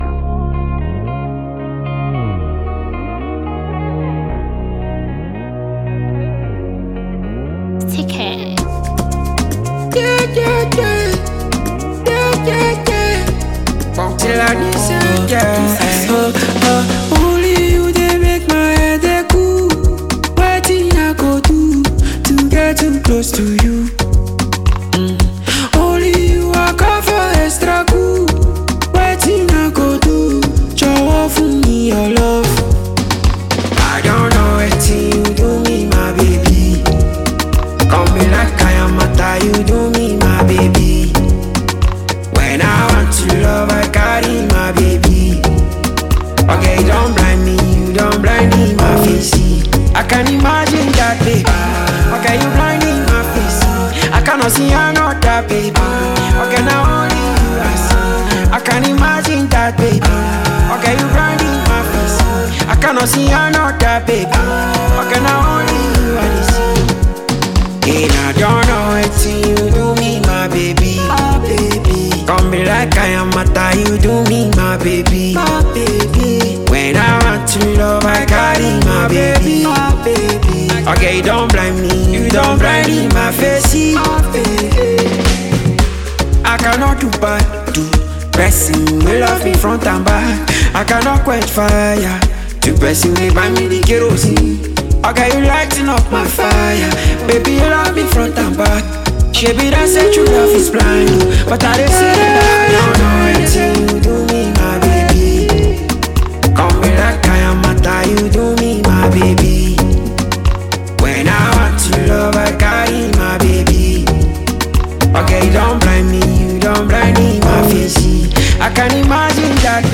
Afrobeat
love song